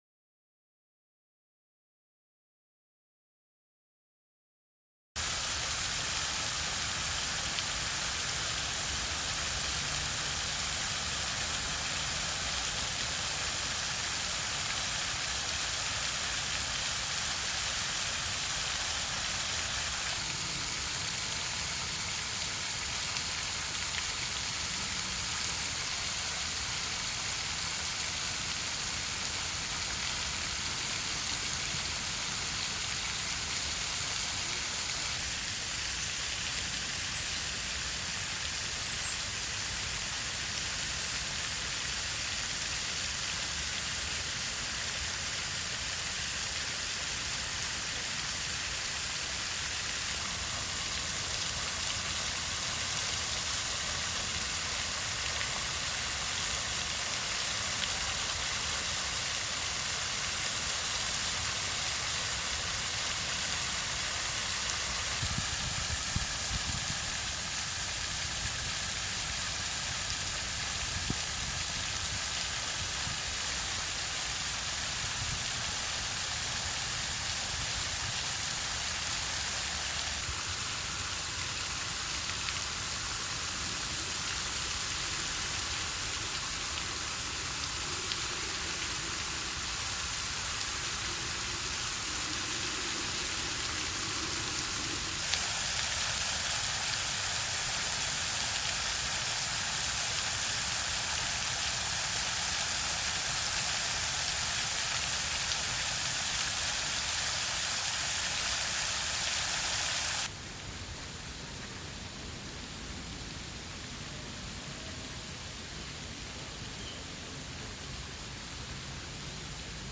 دانلود آهنگ چشمه 3 از افکت صوتی طبیعت و محیط
دانلود صدای چشمه 3 از ساعد نیوز با لینک مستقیم و کیفیت بالا
برچسب: دانلود آهنگ های افکت صوتی طبیعت و محیط دانلود آلبوم صدای چشمه و فواره از افکت صوتی طبیعت و محیط